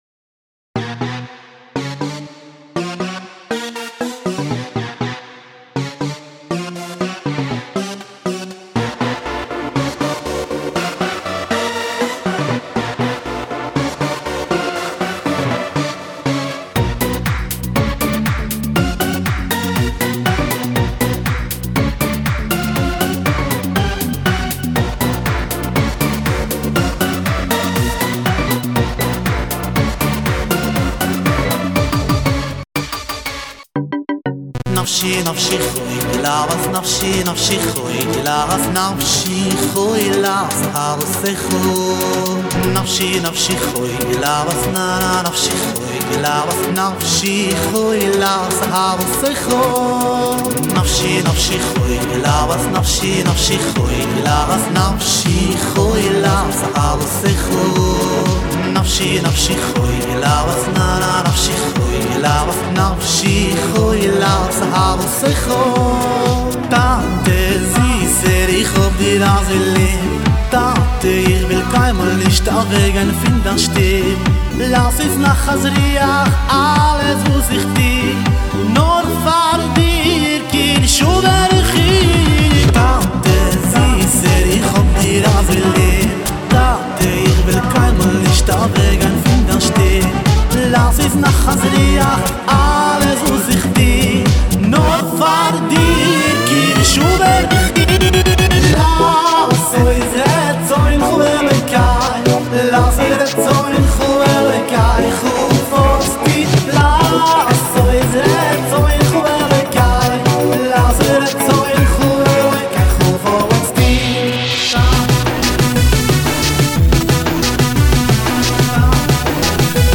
להיט חדש קצבי וסוחף מלא אהבה להשי"ת ולעבודתו יתברך